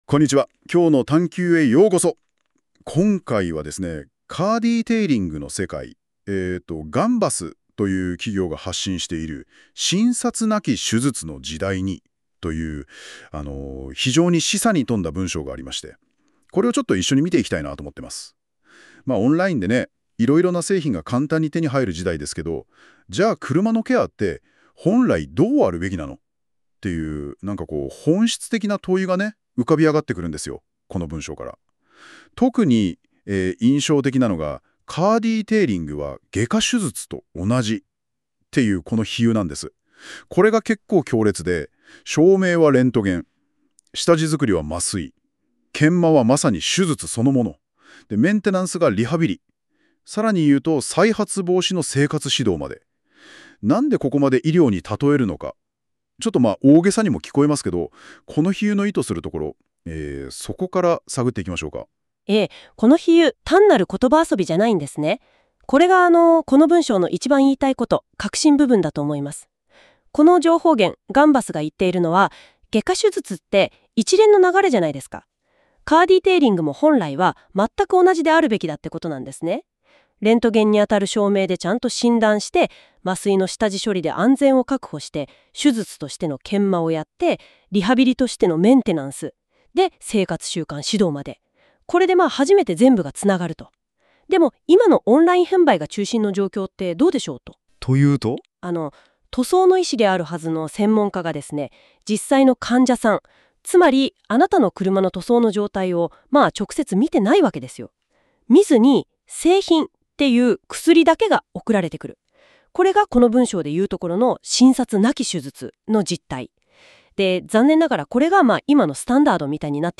なお、このラジオに関しましてはAIで音声を生成しております関係上、 下記の問題が御座います。
②イントネーションを含めた発音があくまでAI。